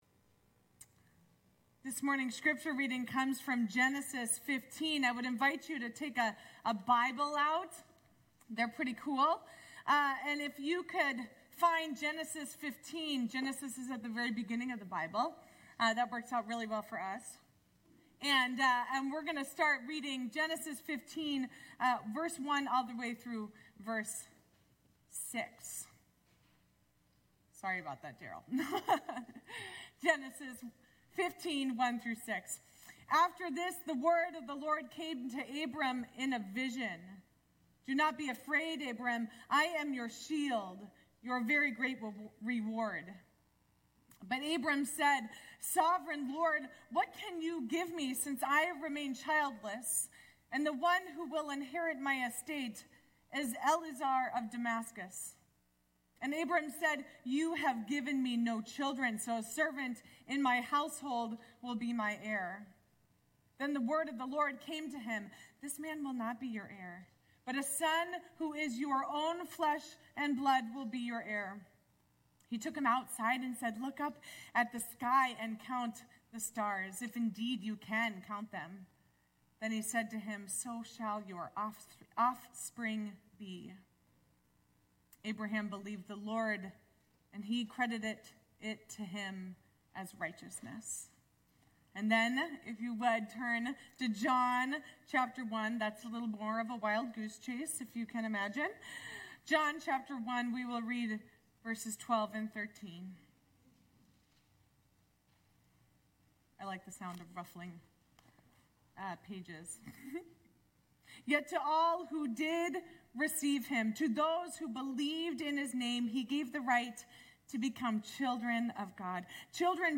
Service